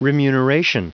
Prononciation du mot remuneration en anglais (fichier audio)
Prononciation du mot : remuneration